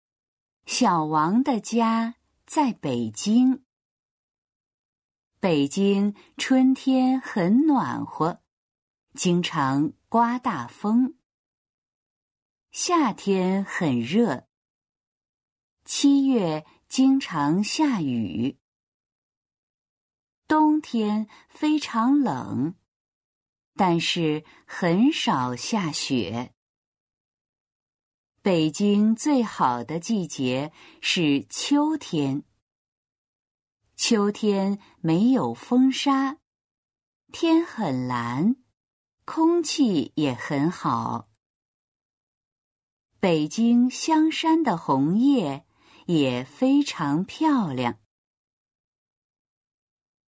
音読